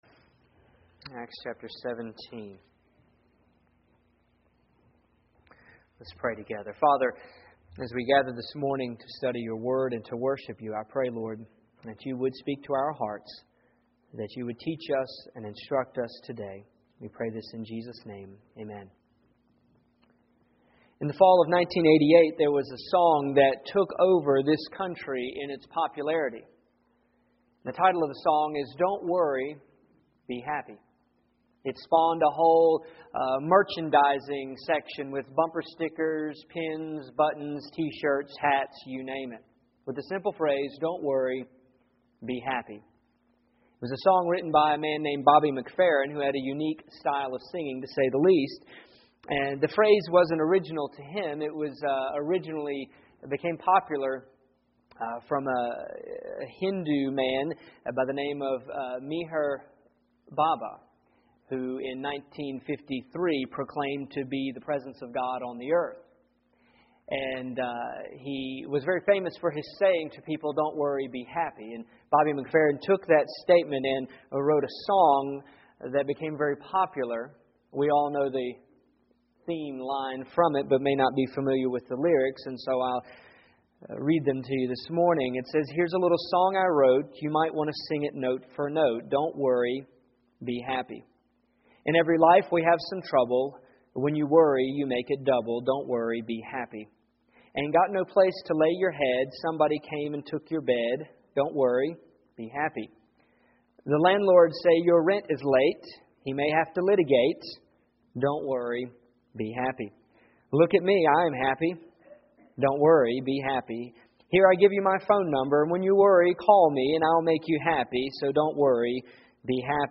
Here is my sermon from this past Sunday, December 28th. I am continuing in our series through Acts.